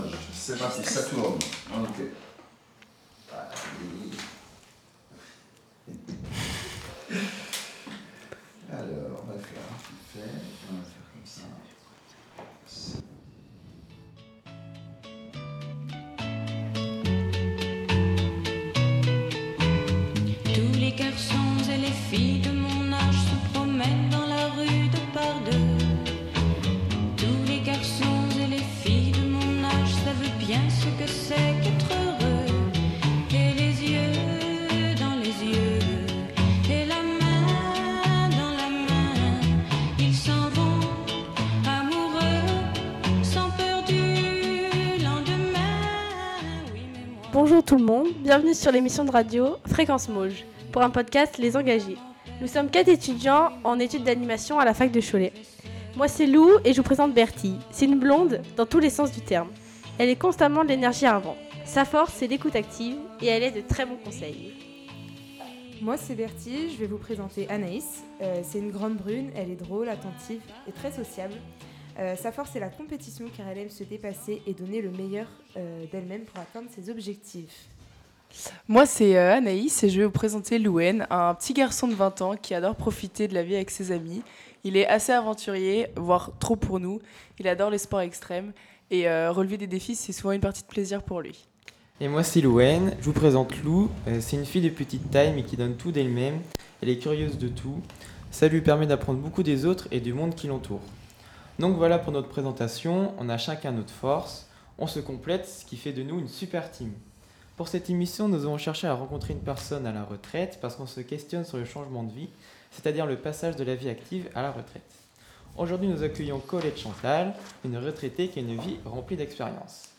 Autour de la table, nous sommes quatre étudiants en animation socioculturelle, passionés par la découverte de nouvelles personnes. Entre confidences, réflexions et anecdotes, cette rencontre sort des sentiers battus - et on vous embarque avec nous. Cette retraitée nous fait part de son parcours et de ses expériences entre vie associative et engagement bénévole, ses journées sont toujours bien occupées.